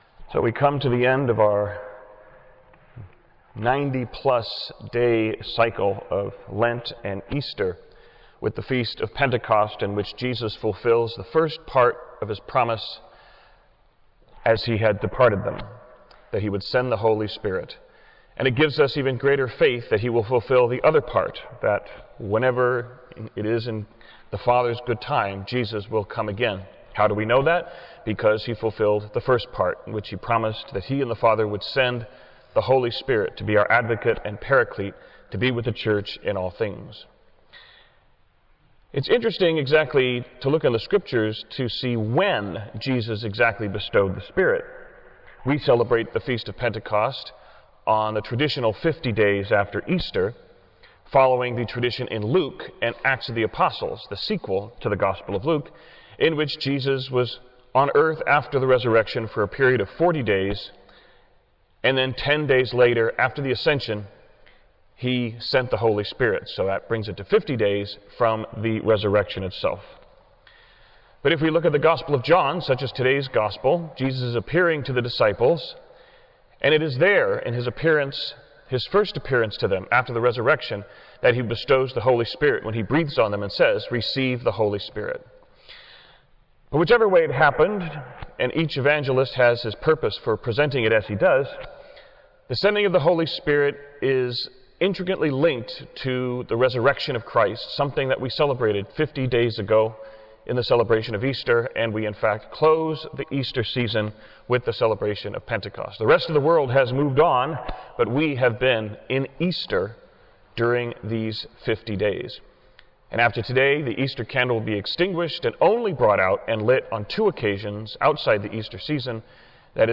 Homily-PENTECOST2019upload.wav